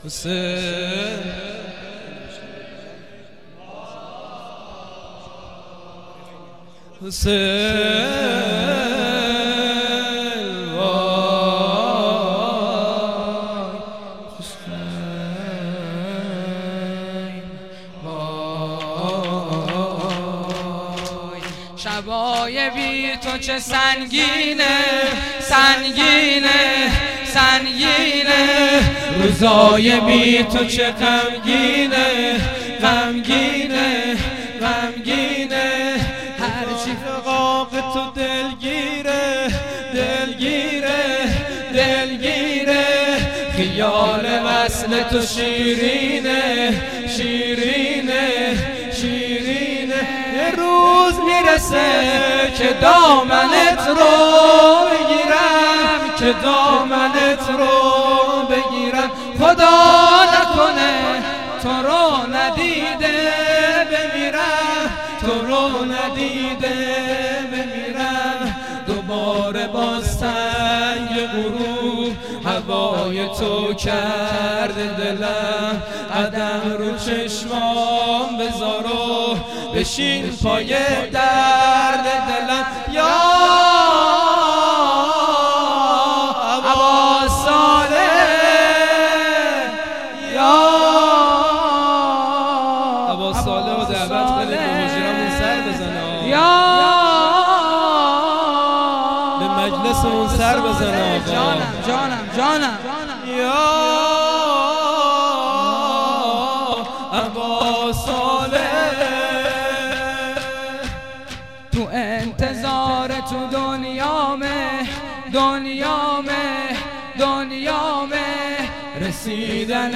خیمه گاه - هیئت قتیل العبرات - مداحی
شب سوم محرم